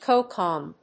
/kóʊkɑm(米国英語), kˈəʊkɔm(英国英語)/